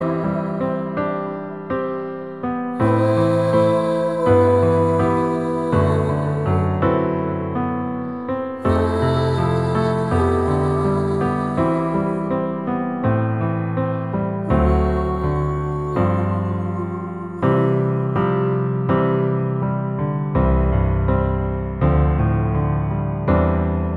Piano Only Version Pop (2000s) 4:24 Buy £1.50